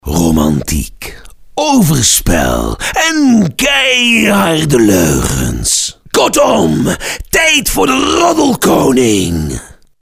Over de Top